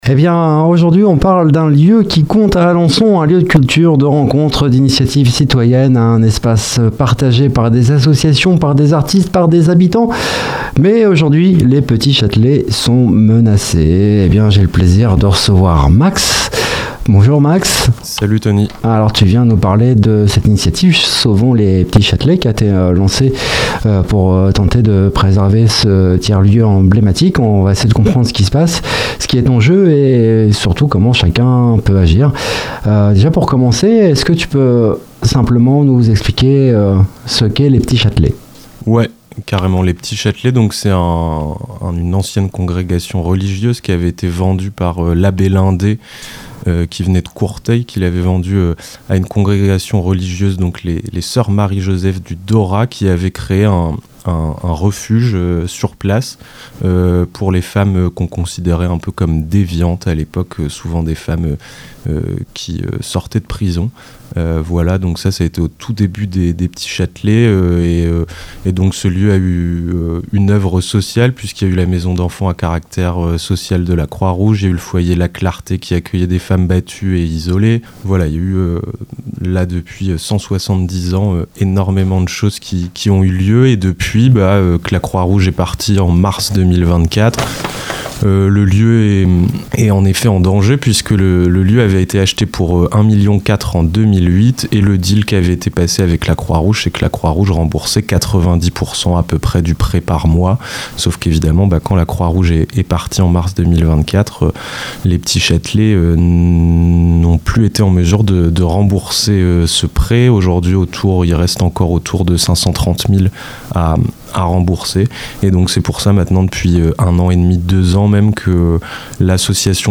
Dans cette interview